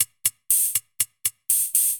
UHH_ElectroHatD_120-03.wav